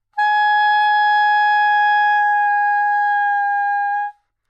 萨克斯高音单音（吹得不好） " 萨克斯高音G5音高不好，音准不好音色不好
描述：在巴塞罗那Universitat Pompeu Fabra音乐技术集团的goodsounds.org项目的背景下录制。单音乐器声音的Goodsound数据集。
标签： 好声音 萨克斯 单注 多样本 Gsharp5 女高音 纽曼-U87
声道立体声